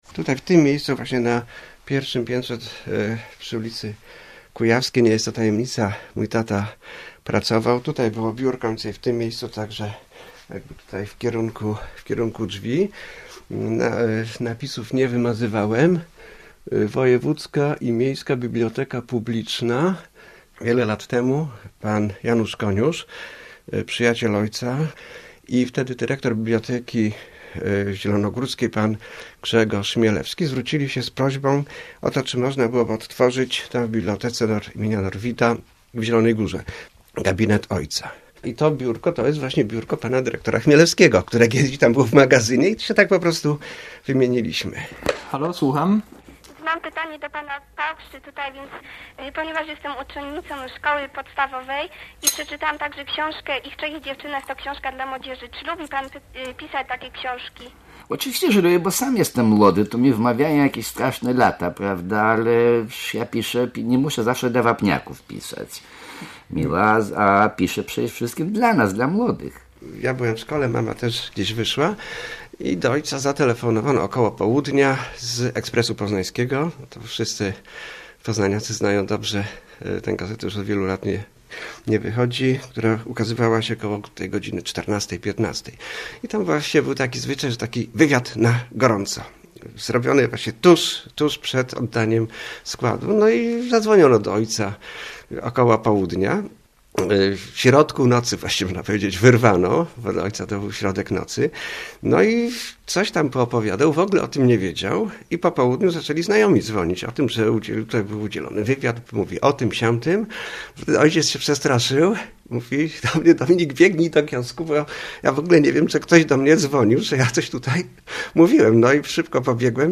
Wierzę w ludzi. Eugeniusz Paukszta - reportaż